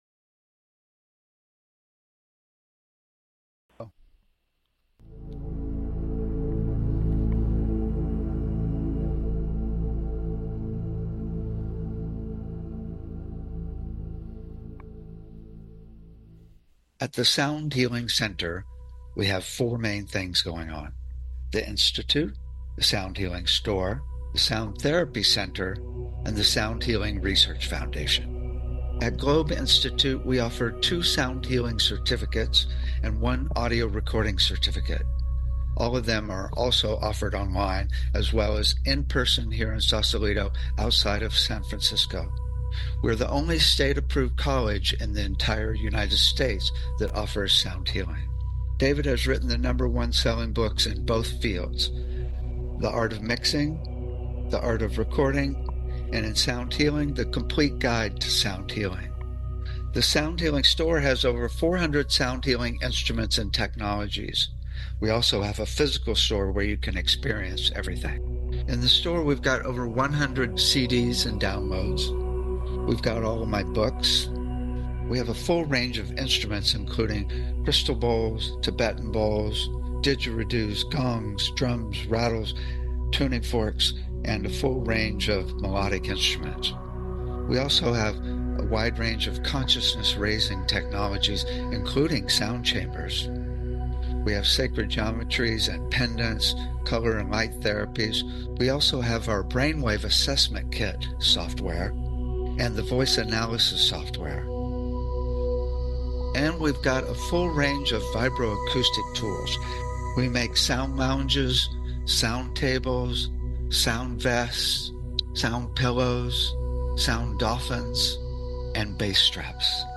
Talk Show Episode, Audio Podcast, Sound Healing and Creativity and Intuition on , show guests , about Creativity and Intuition, categorized as Education,Energy Healing,Sound Healing,Love & Relationships,Emotional Health and Freedom,Mental Health,Science,Self Help,Spiritual